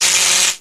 LightningHitRod.mp3